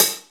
paiste hi hat6 close.wav